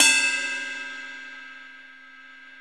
Drums/CYM_NOW! Cymbals
CYM KLB BELL.wav